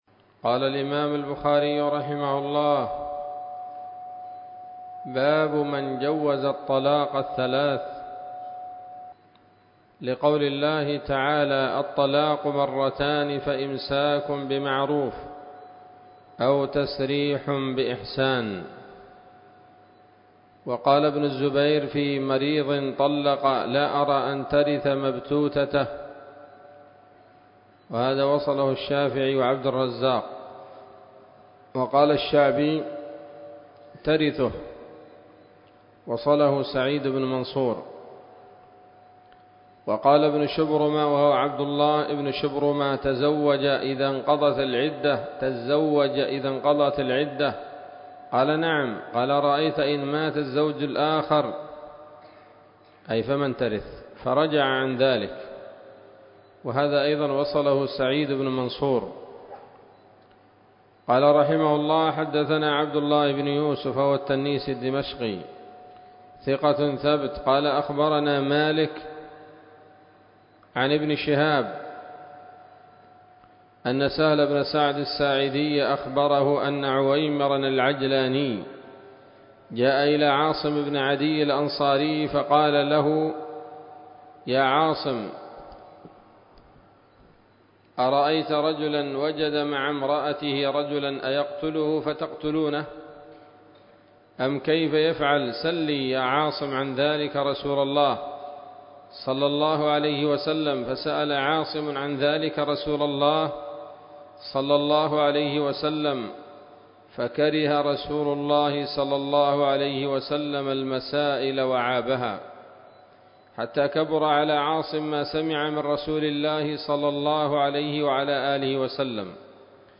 الدرس الرابع من كتاب الطلاق من صحيح الإمام البخاري